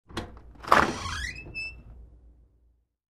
Звуки деревянной двери
Скрип деревянной входной двери при открывании